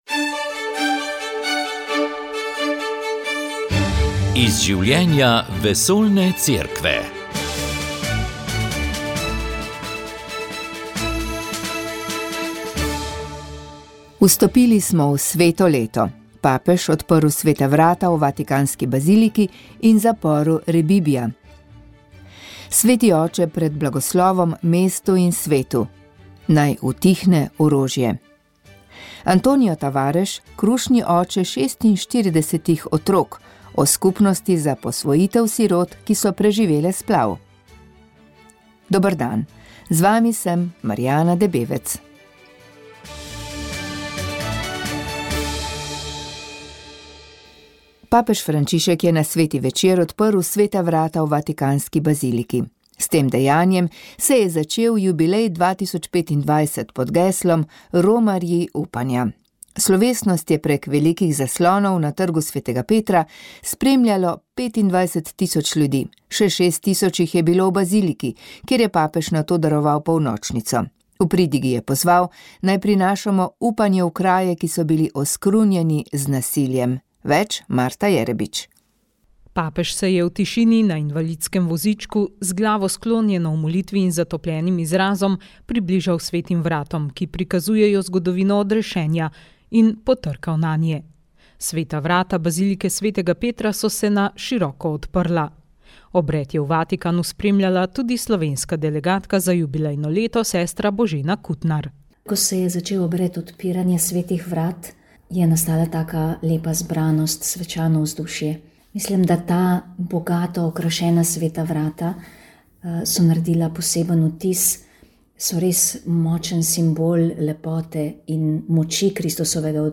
Prisluhnite pogovoru z zanimivim multiinstrumentalistom!